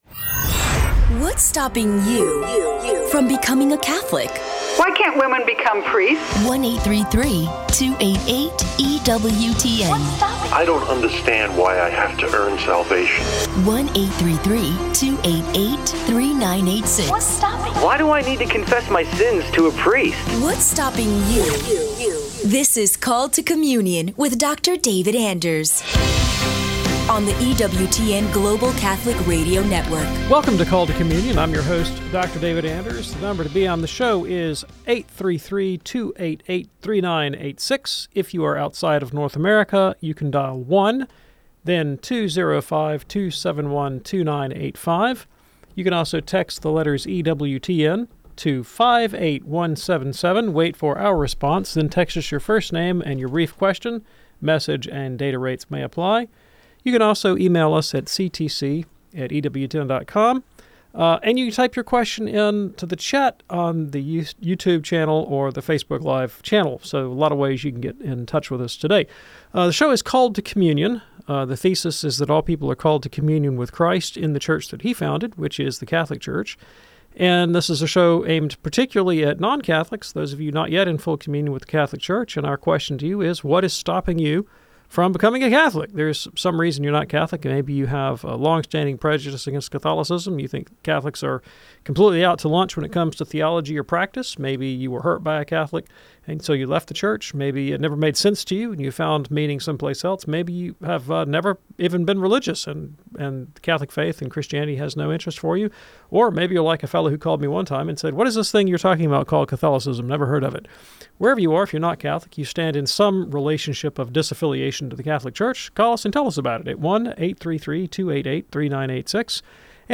A caller asks if an interrupted rosary still has merit, while another explores the necessity of regular Bible reading for Catholics. The conversation shifts to karma, where distinctions are made between Catholic beliefs and other traditions.